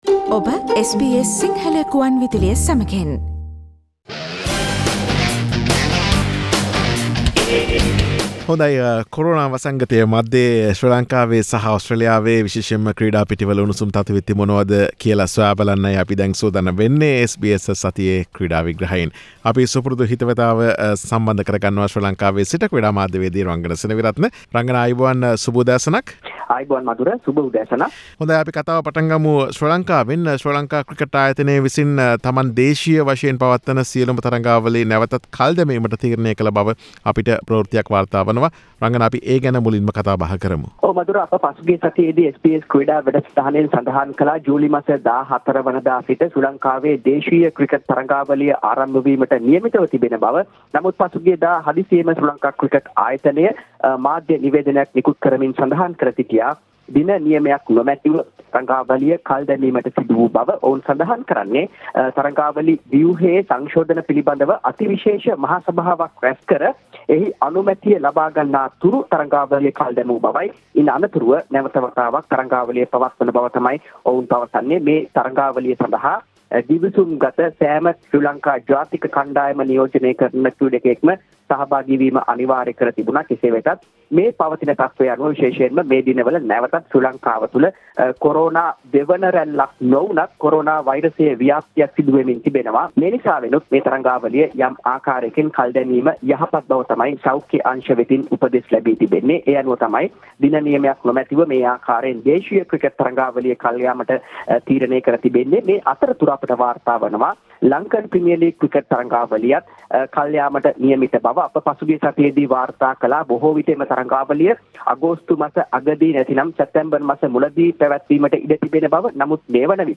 SBS Sinhalese Sports Wrap